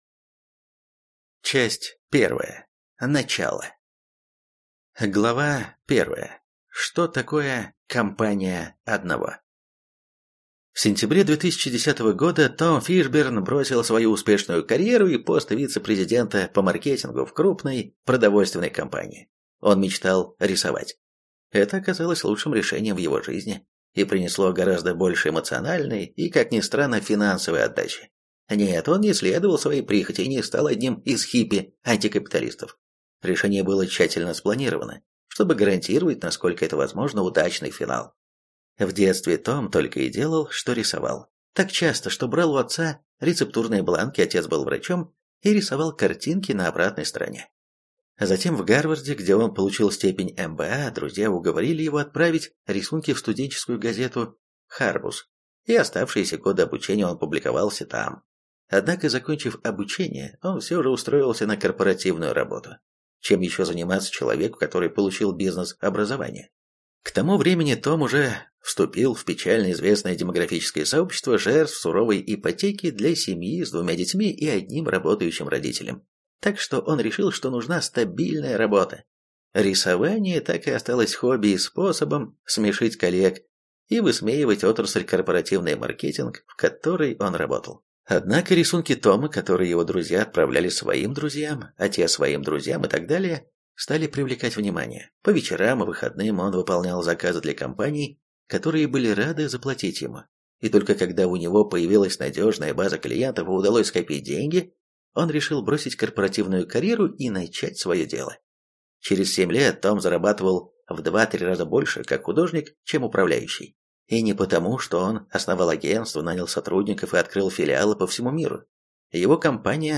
Аудиокнига Компания одного человека. Почему не обязательно расширять бизнес | Библиотека аудиокниг